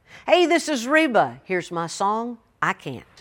LINER Reba McEntire (I Can't)